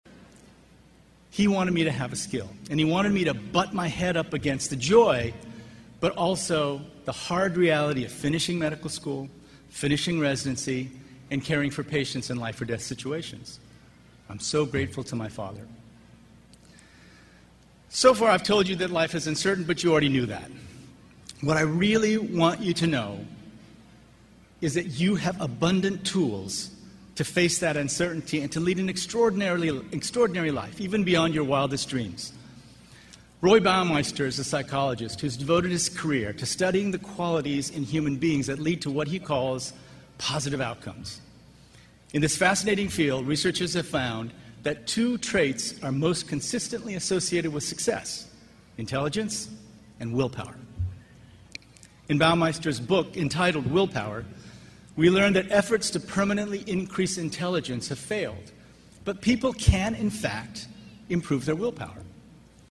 公众人物毕业演讲 第67期:金墉美国东北大学(6) 听力文件下载—在线英语听力室